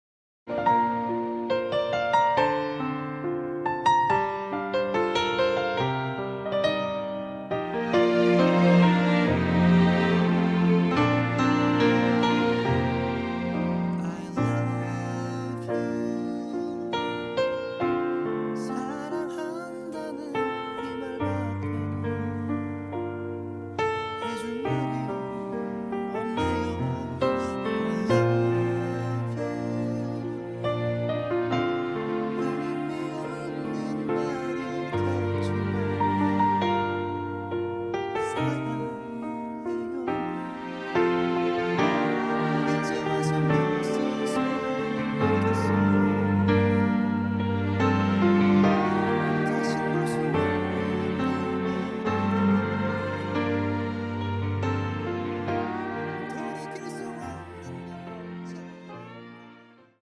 음정 원키 4:42
장르 가요 구분 Voice Cut